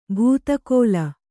♪ bhūta kōla